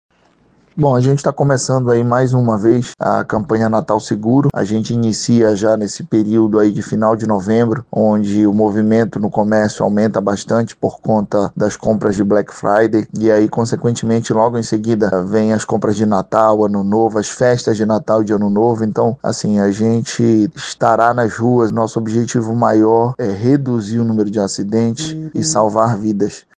A ação visa conscientizar a população sobre segurança no trânsito e reduzir o número de acidentes, como explica o diretor-presidente do órgão, Diego Mascarenhas.
Sonora-Diego-Mascarenhas-–-diretor-presidente-do-EMTT.mp3